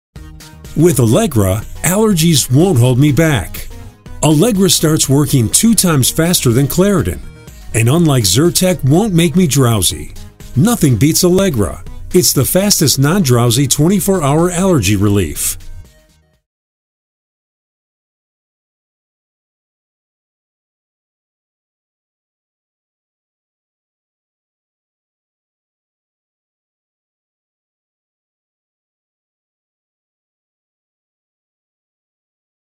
Midwest accent
Middle Aged